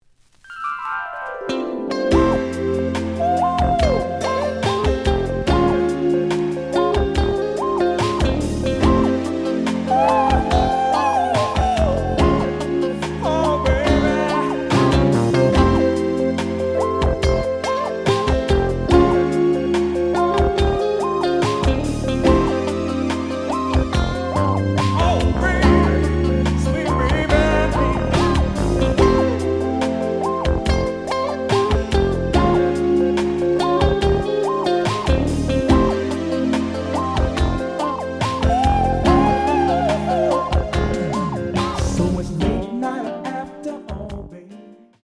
The audio sample is recorded from the actual item.
●Genre: Disco
Slight noise on beginning of A side, but almost good.)